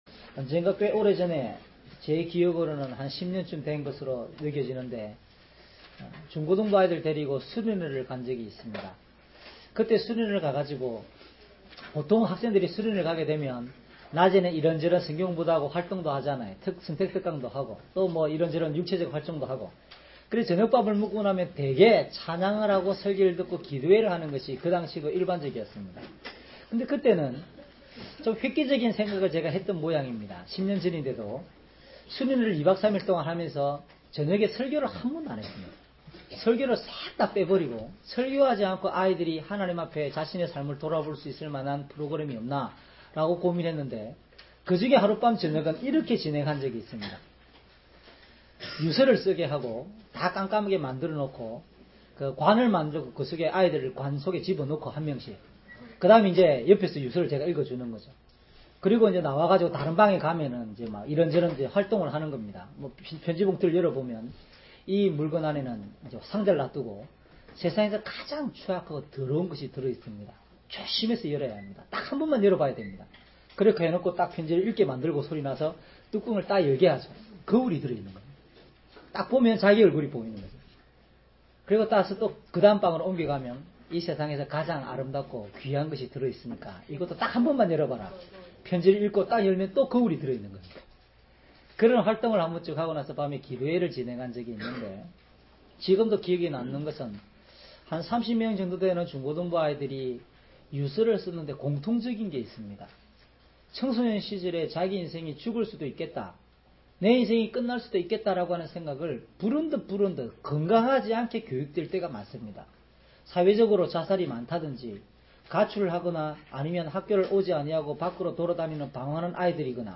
주일설교 - 08년 12월 28일 "아름다운 믿음과 헌신의 삶을 이어갑시다."